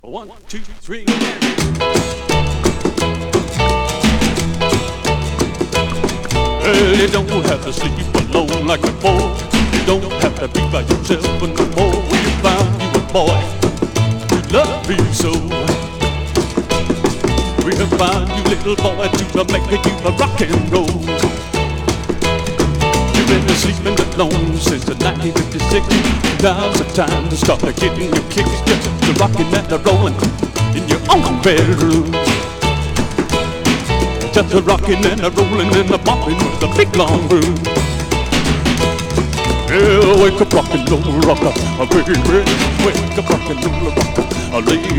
Rock, Rockabilly　USA　12inchレコード　33rpm　Mono